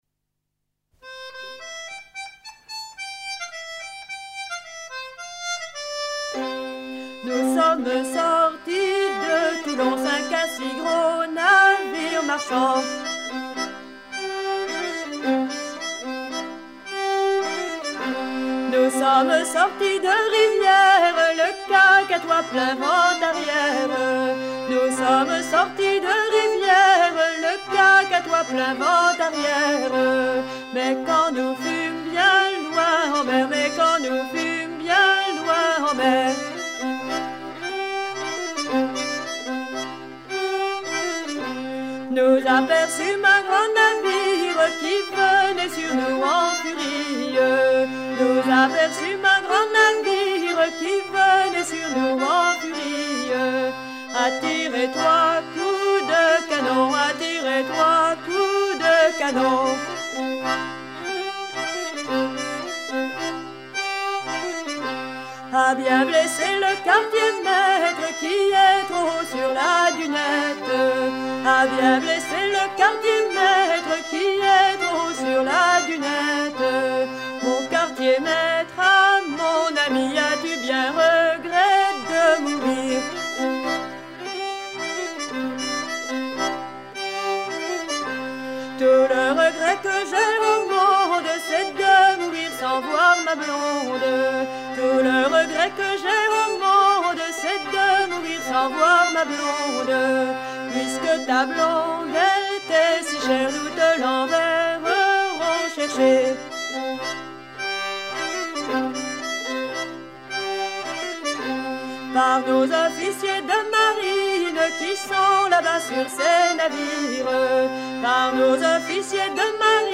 Version recueille en 1973
Genre strophique
Pièce musicale éditée